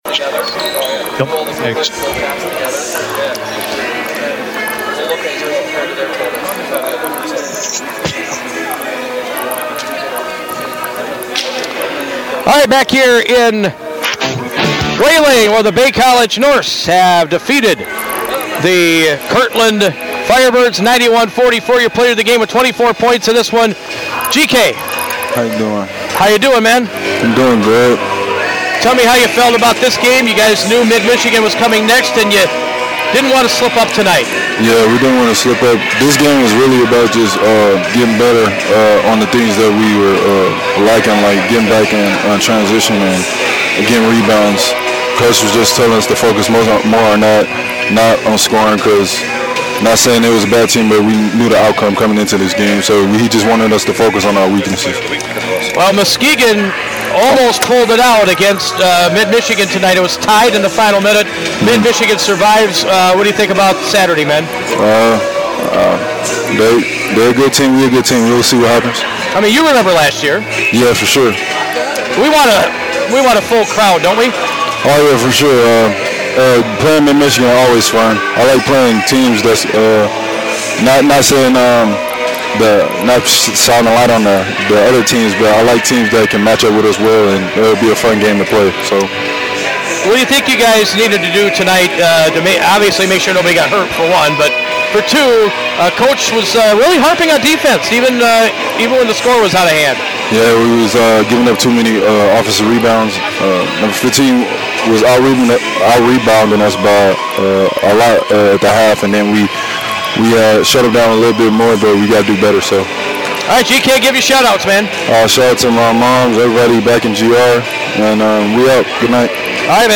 post-game comments